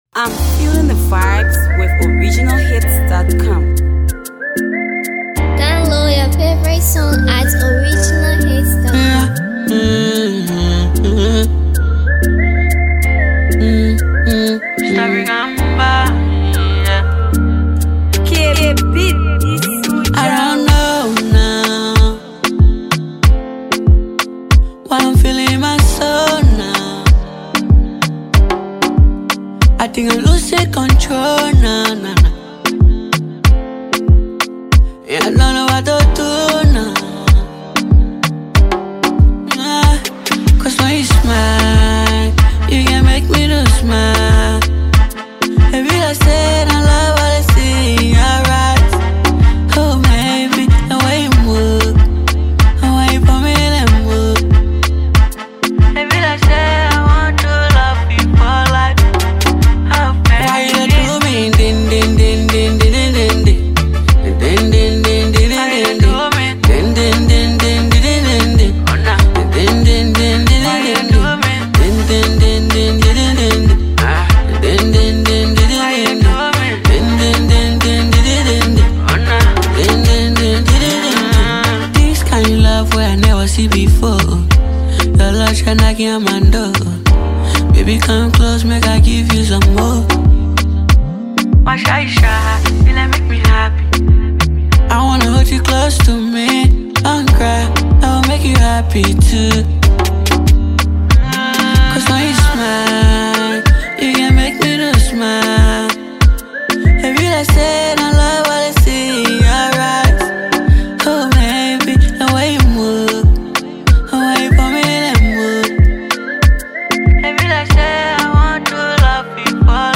stunning melody banger